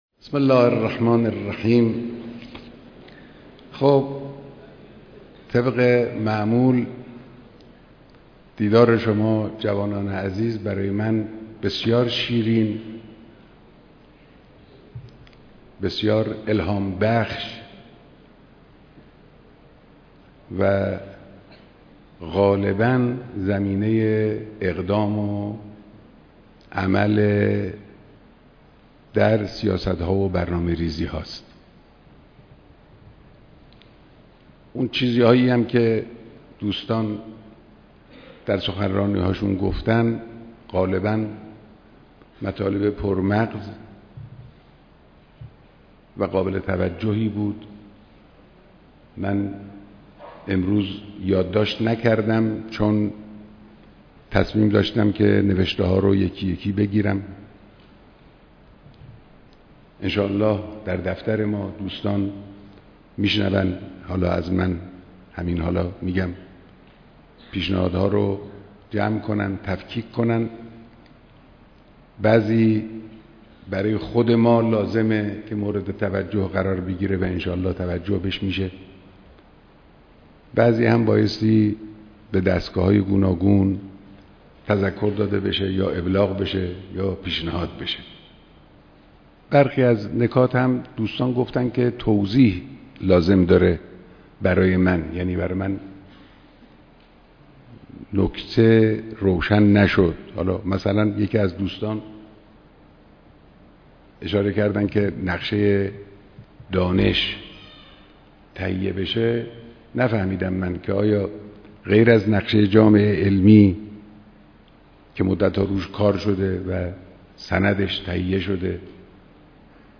بیانات در دیدار شركت‌كنندگان در هفتمین همایش ملی نخبگان جوان